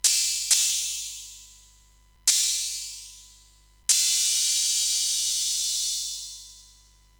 Hi-Hat